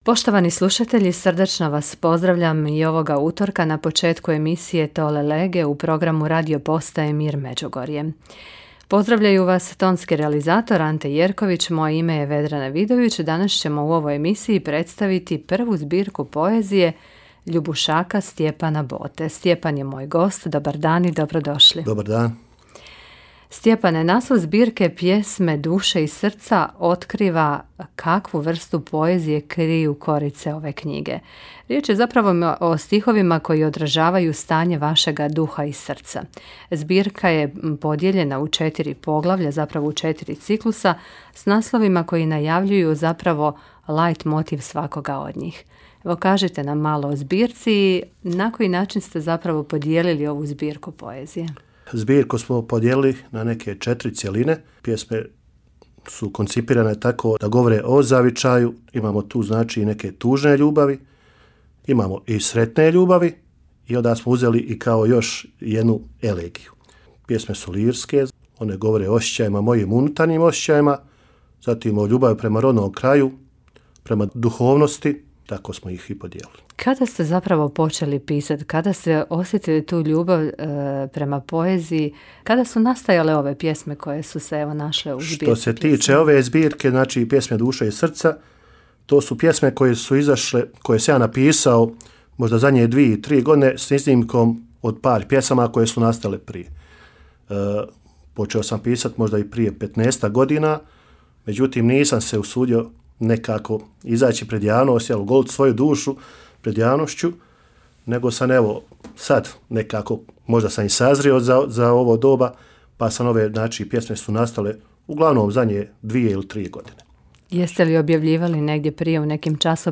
gostujući u emisiji Tolle Lege na radiopostaji Mir Međugorje